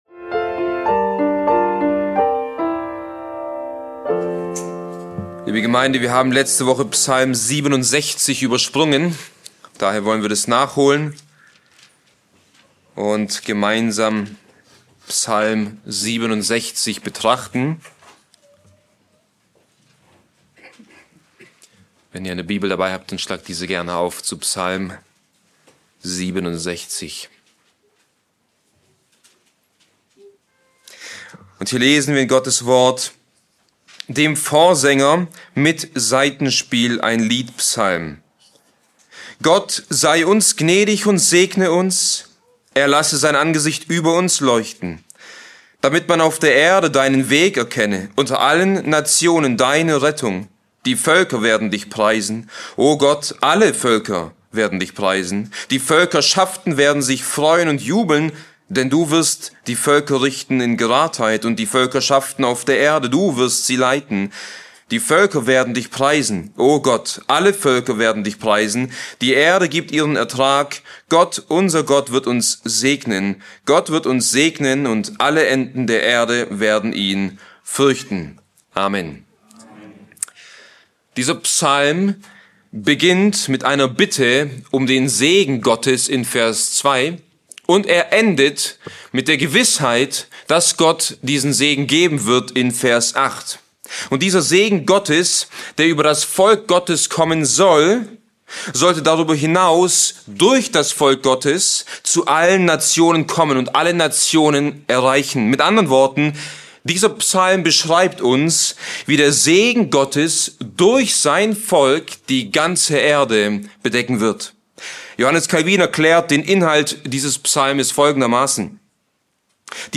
Bibeltreue Predigten der Evangelisch-Baptistischen Christusgemeinde Podcast